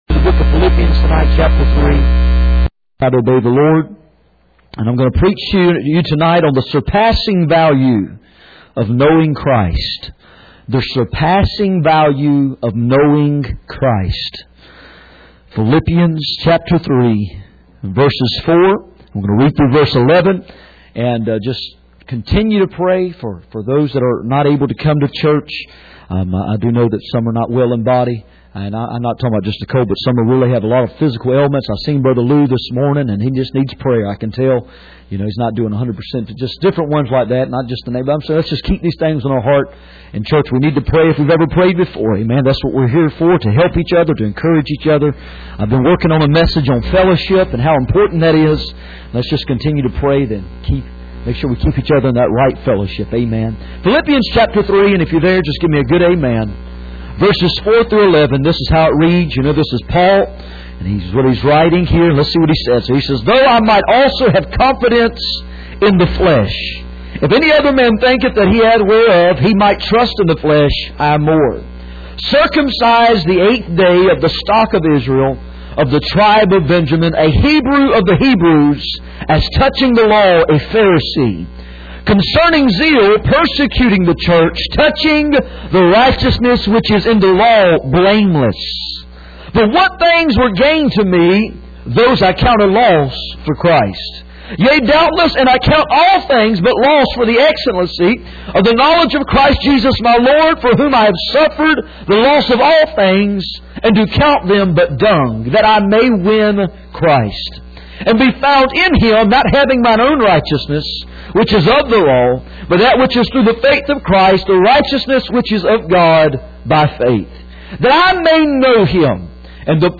Passage: Philippians 3:4-11 Service Type: Sunday Evening